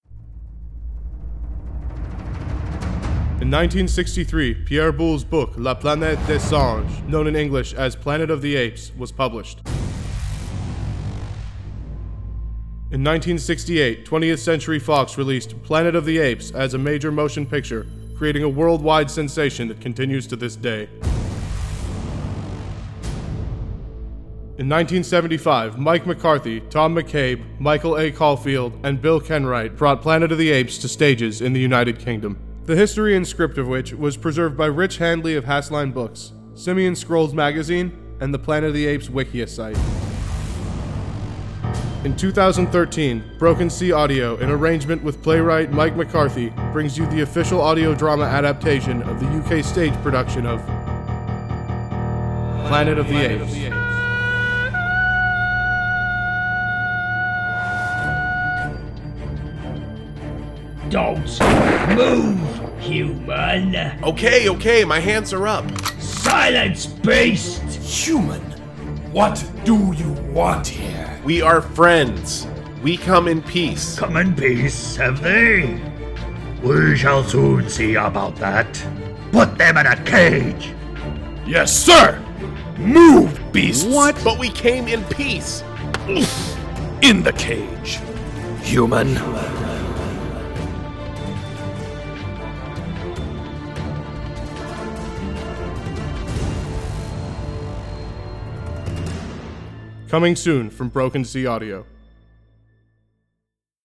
Coming Soon Trailer Tue